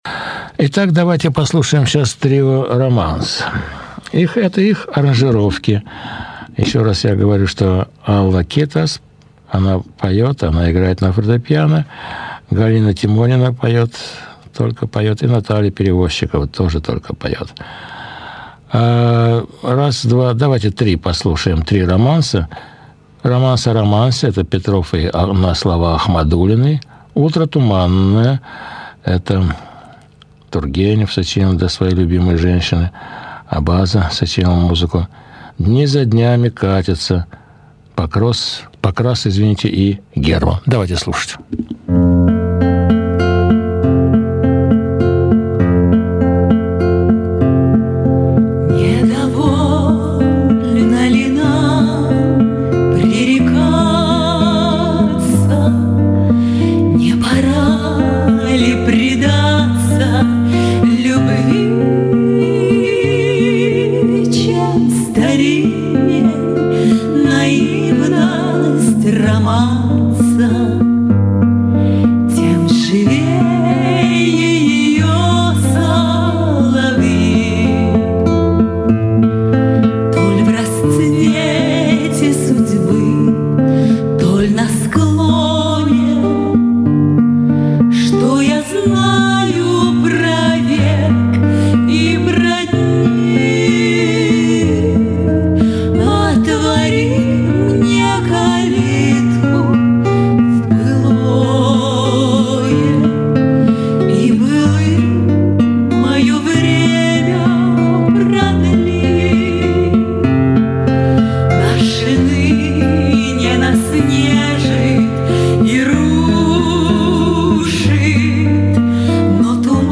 Концерт нон стоп.